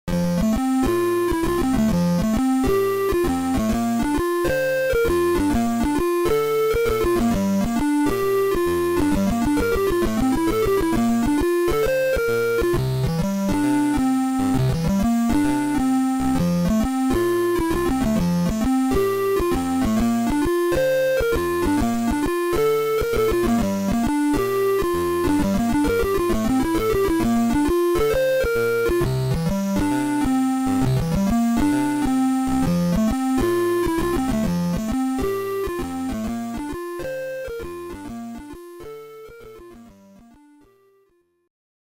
Game Manual:N/A              Game Music: